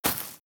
GravelStep1.wav